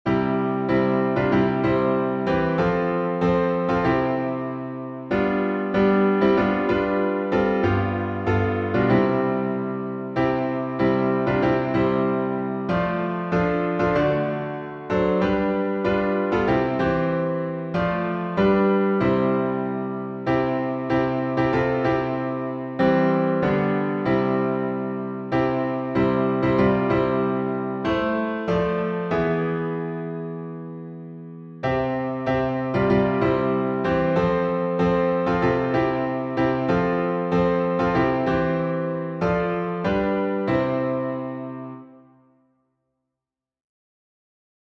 Key signature: C major (no sharps or flats) Time signature: 4/4